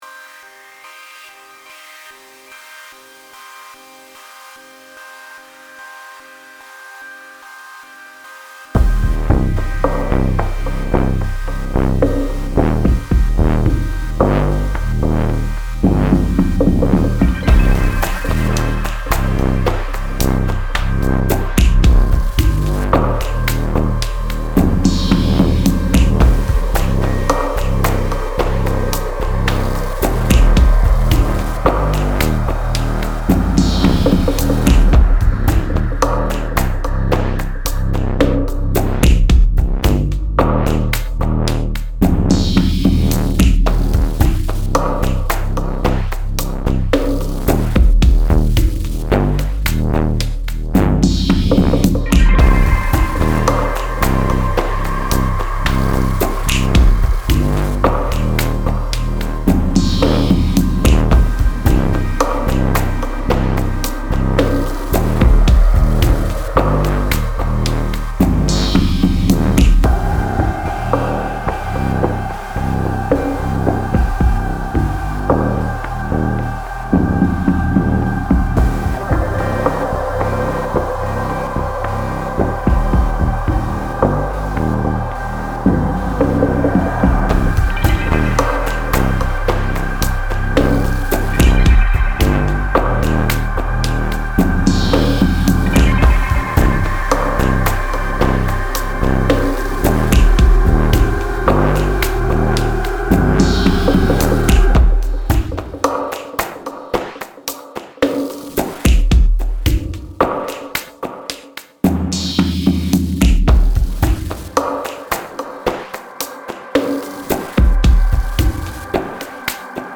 I was in the mood for some dubby vibes, the minitaur made me do it.